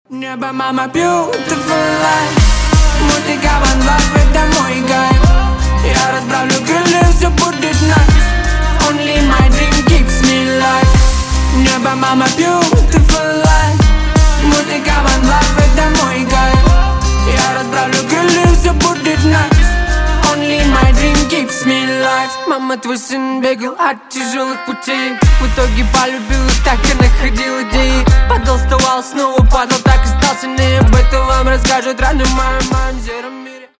Категория: Рингтоны на сынаРэп рингтоны